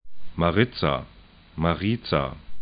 ma'rɪtsa